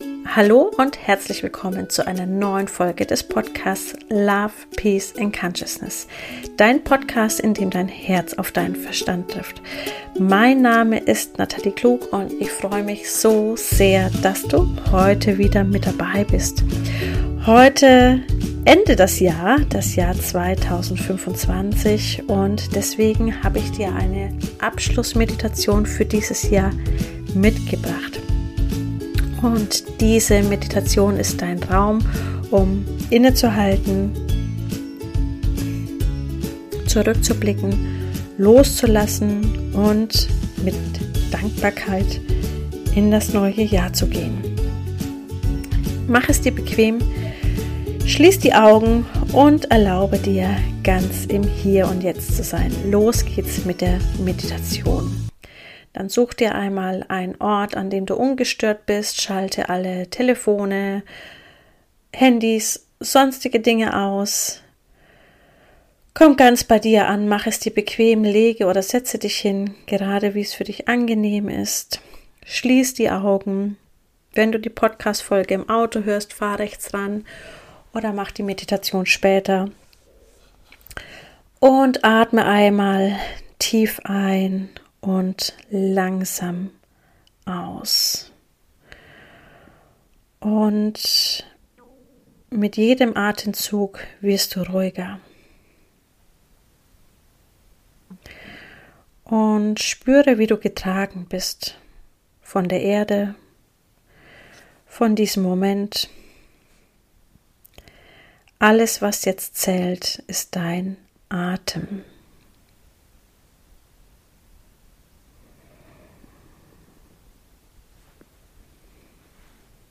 Jahresabschluss 2025 Loslassen, Dankbarkeit & neue Klarheit – geführte Meditation